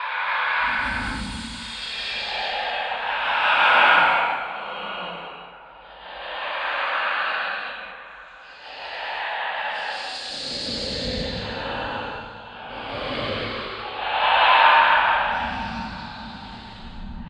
ghost_whisper.ogg